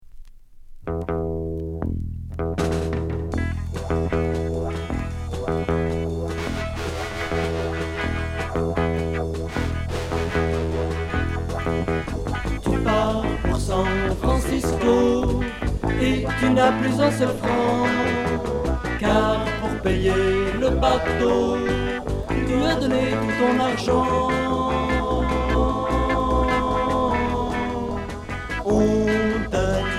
Pop rock religieux